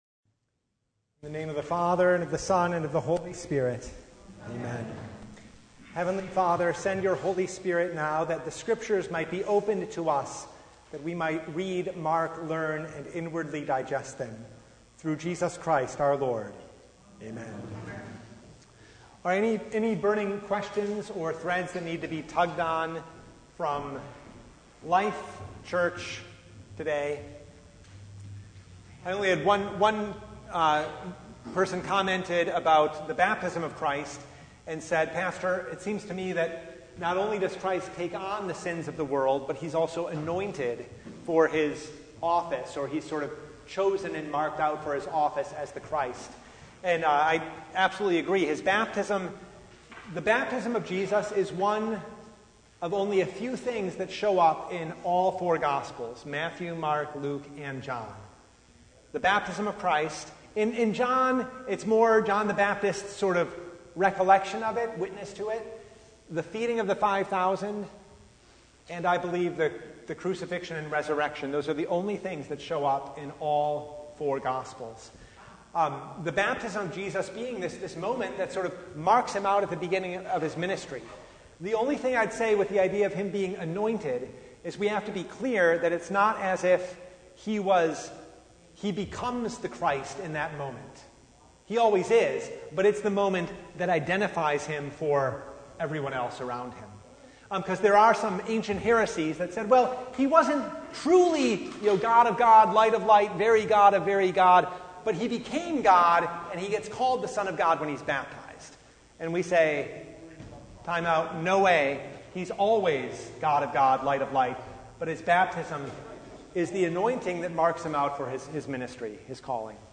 Service Type: Bible Hour
Bible Study